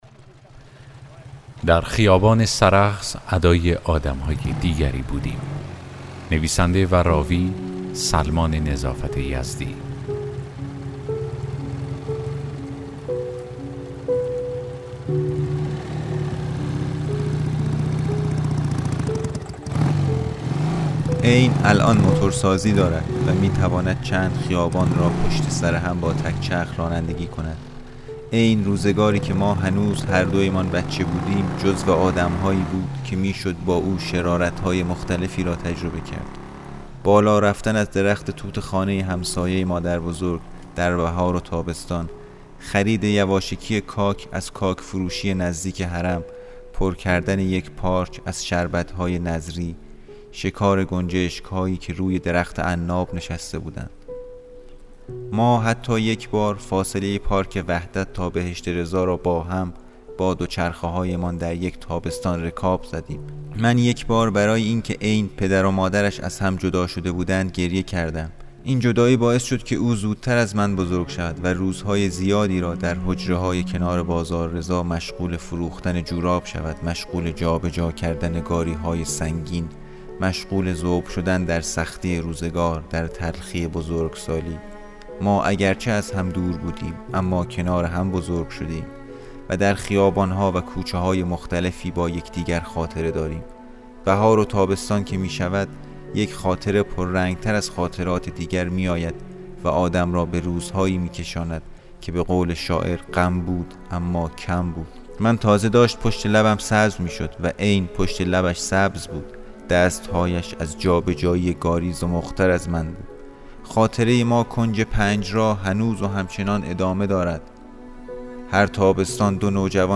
داستان صوتی: در خیابان سرخس ادای آدم‌های دیگری بودیم